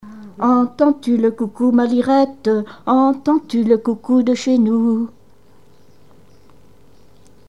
Genre brève
Témoignages et chansons
Catégorie Pièce musicale inédite